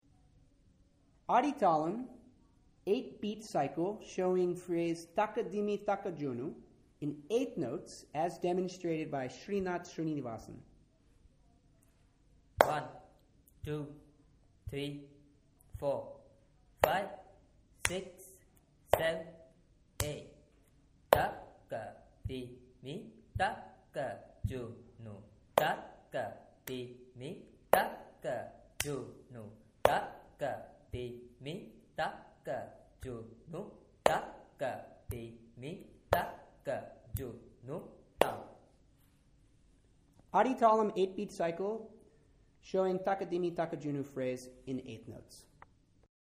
Adi Thalum is an 8 beat cycle found in Carnatic Music. Adi Thalum is shown through a series of claps and waves.
Taka Dimi Taka Junu in 8th Notes
adi_thalum_takadimi_takajunu_8thnotes_garagebandbounce.mp3